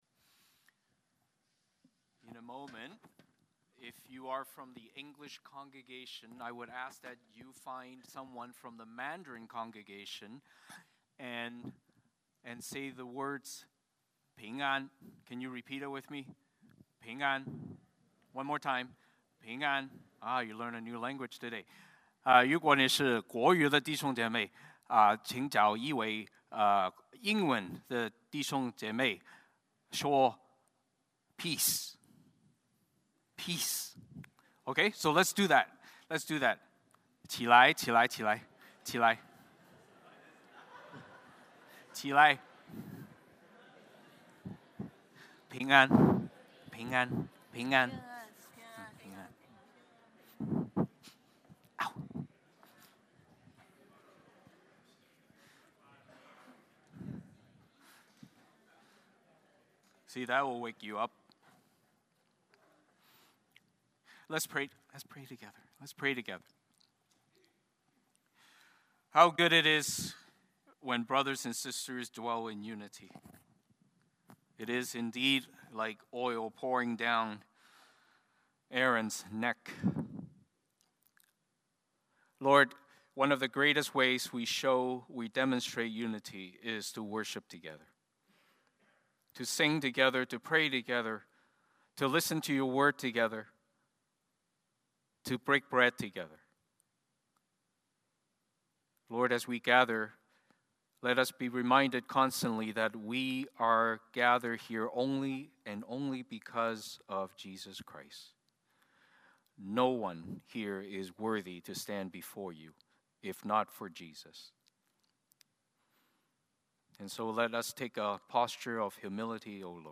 Exodus 12:1-13 Service Type: Sunday Morning Service Passage